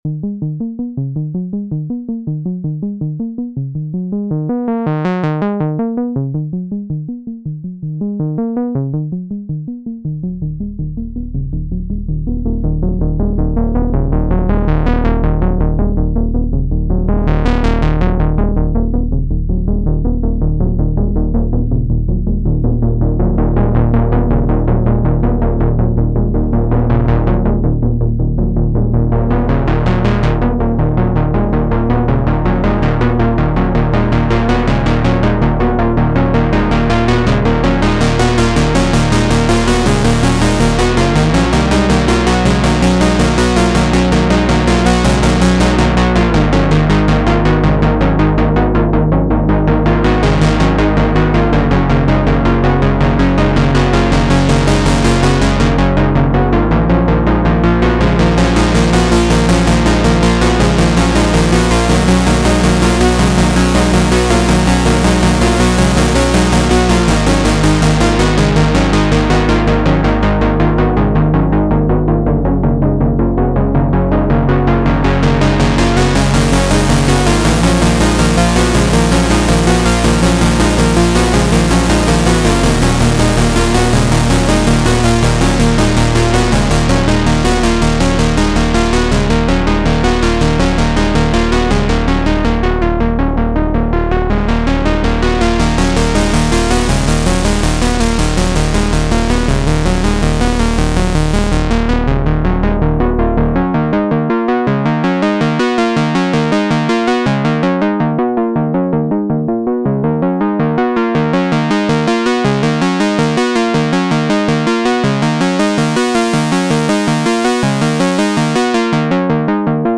I didn’t have the right adapters to connect the A4, so I sequenced a little 14 step pattern on the Digitone 2 and recorded the output of the lowpass filter directly into my audio interface, no post processing aside from normalizing the recording.